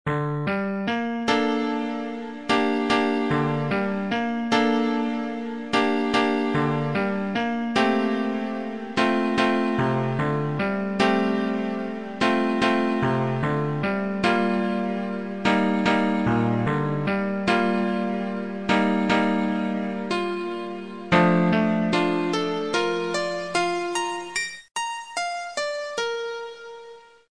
まだ未完成ですが、実験的に自作の曲をアップしてみます。